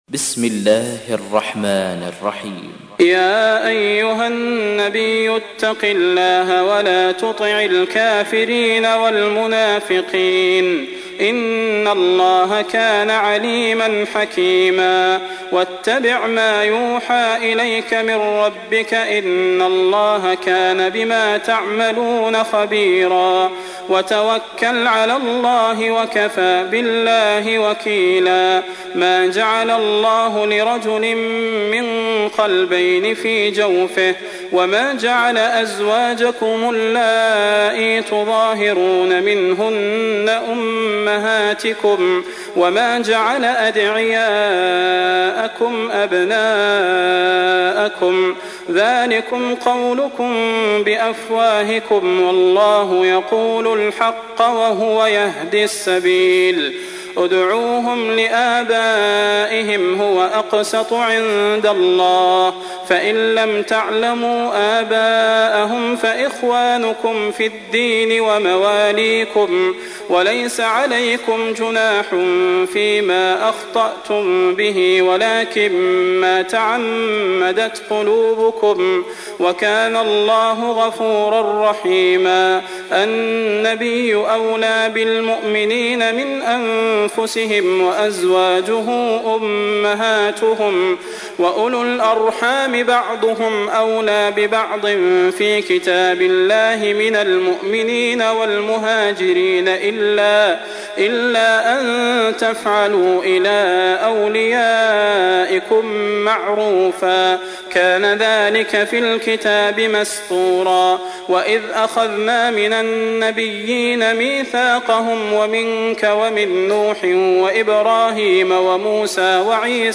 تحميل : 33. سورة الأحزاب / القارئ صلاح البدير / القرآن الكريم / موقع يا حسين